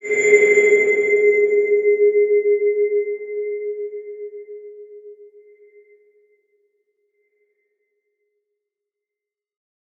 X_BasicBells-G#2-mf.wav